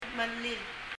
mellil[mɛli(:)l]黄色yellow